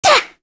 Shamilla-Vox_Attack1_kr.wav